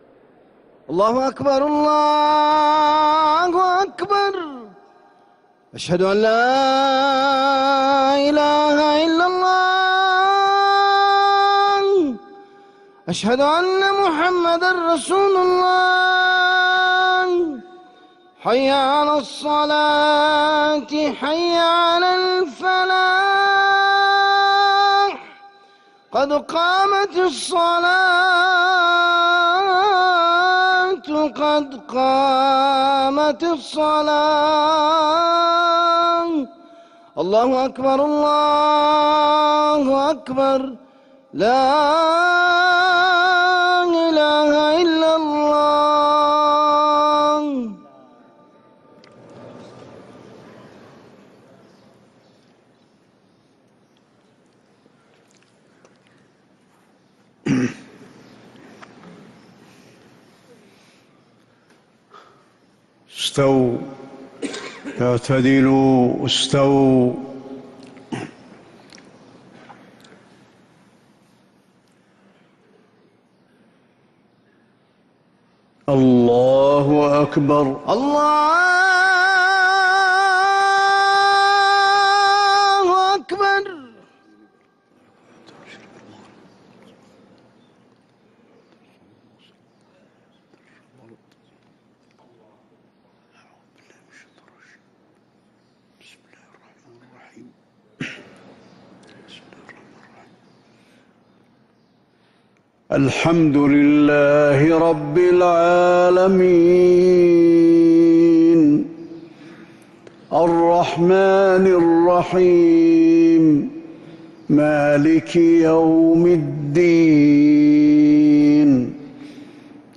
Madeenah Fajr - 14th January 2026
Madeenah Fajr (Surah Haqqah) Sheikh Hudhayfi Download 128kbps Audio